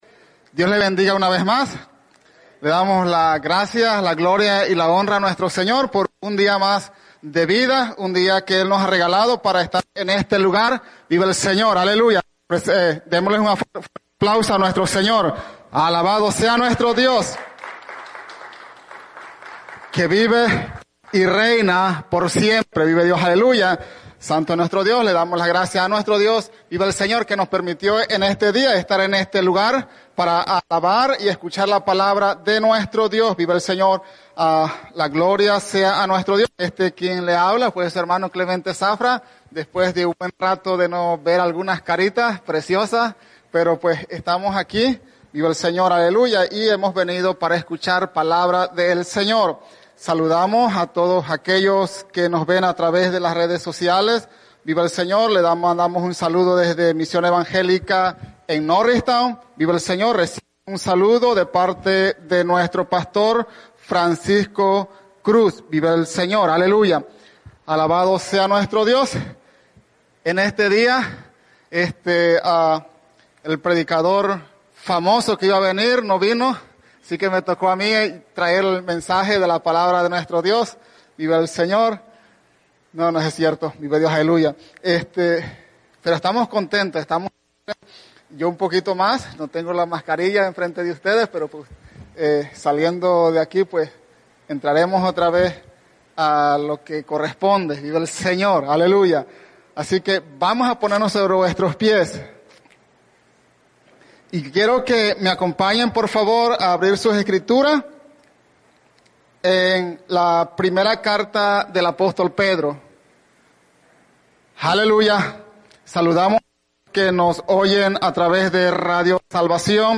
Mensaje
en la Iglesia Misión Evangélica en Norristown, PA